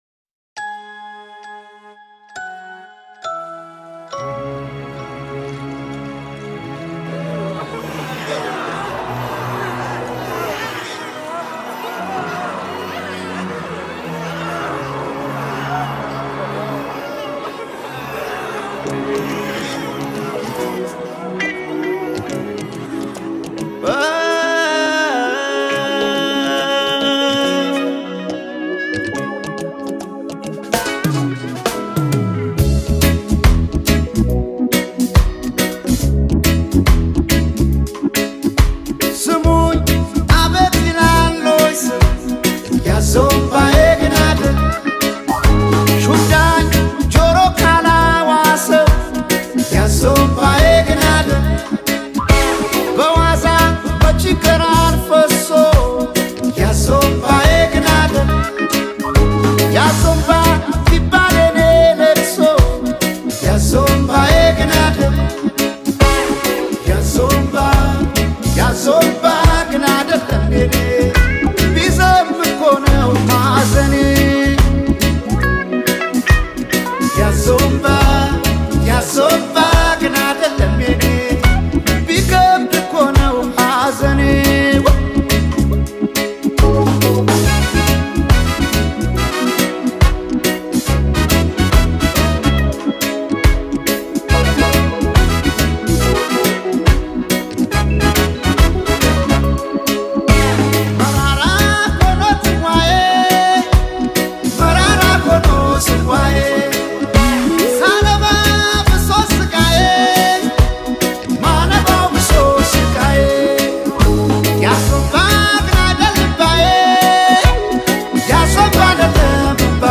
It delivers a sound that feels both fresh and consistent.